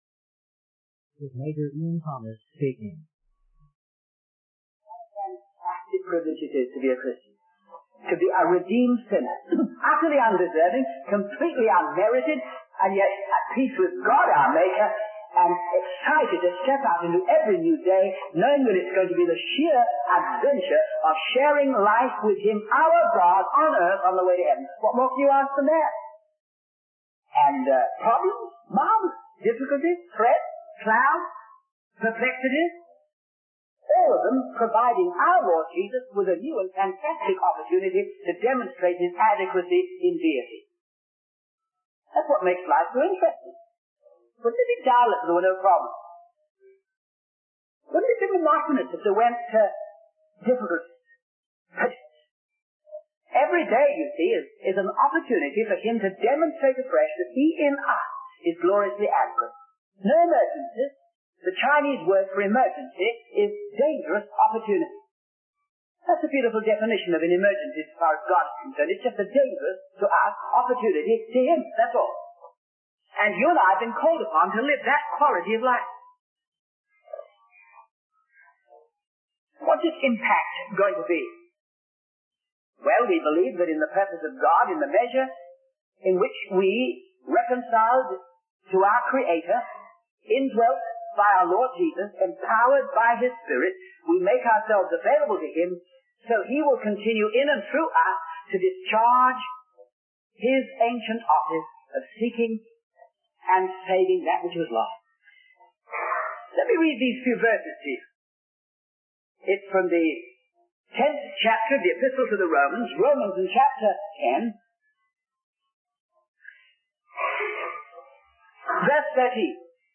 In this sermon, the speaker emphasizes the importance of recognizing our dependence on God and His ability to work through us in every situation.